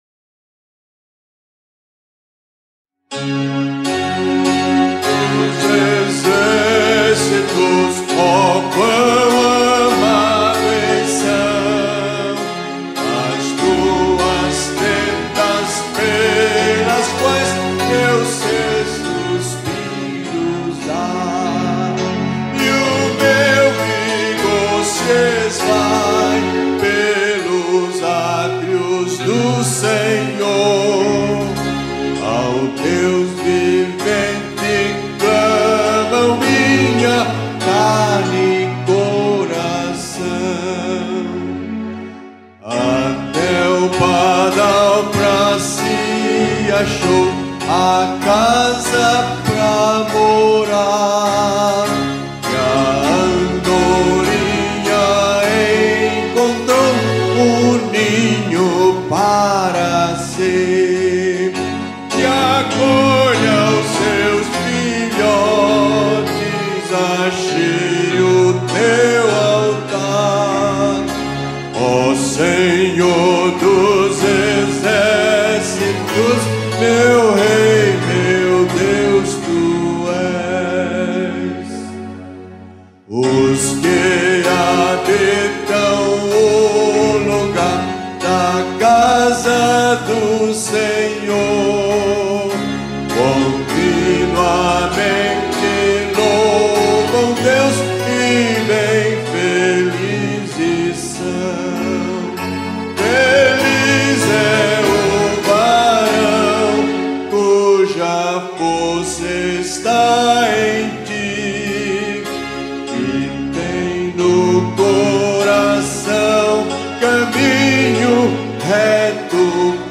Métrica: 7. 6. 7. 6
salmo_84B_cantado.mp3